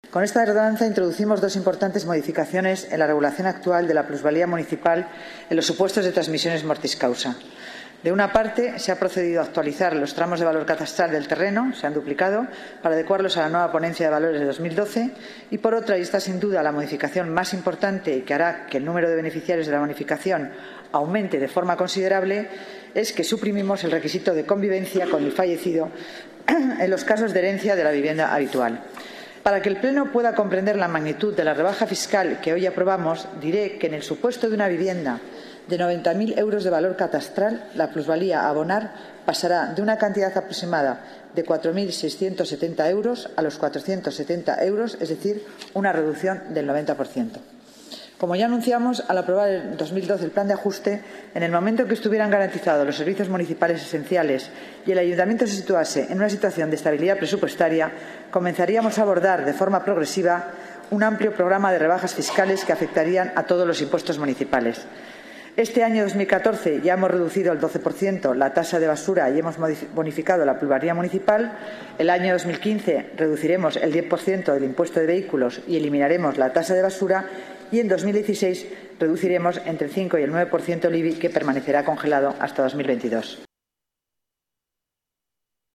Nueva ventana:Declaraciones delegada Economía y Hacienda, Concepción Dancausa: modificación Ordenanza Fiscal plusvalía herencias